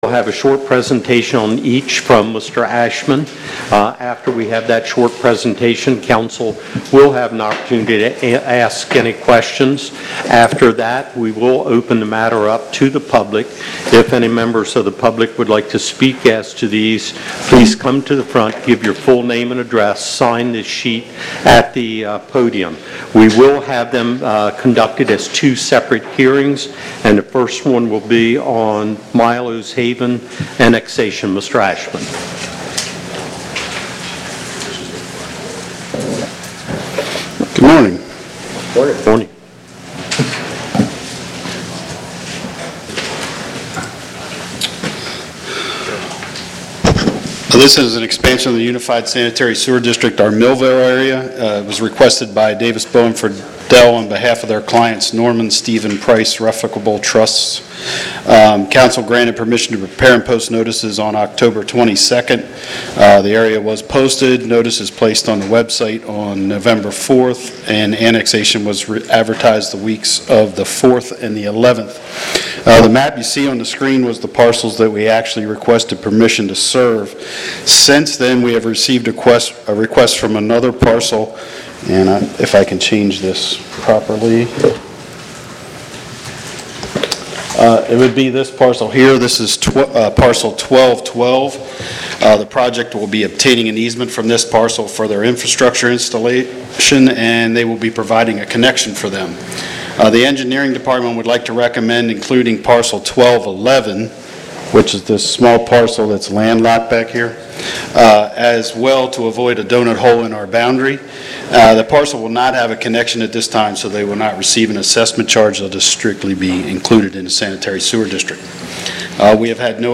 County Council Meeting | Sussex County